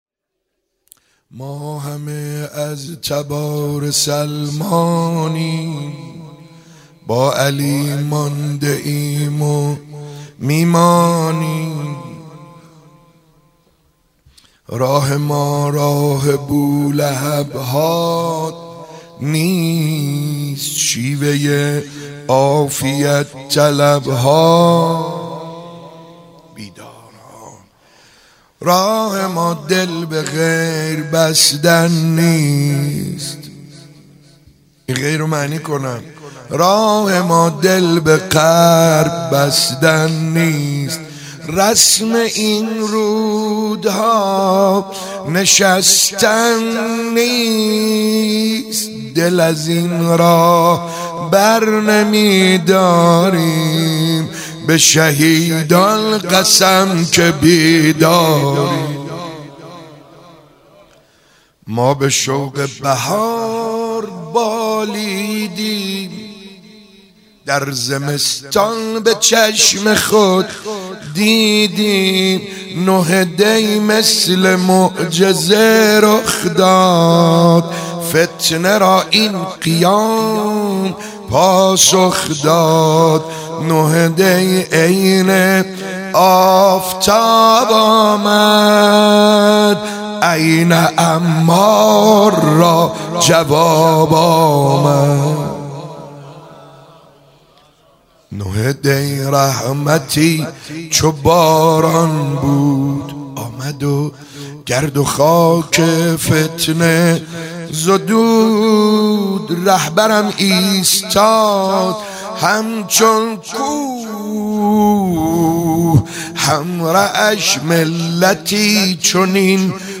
شعرخوانی - ما همه از تبار سلمانیم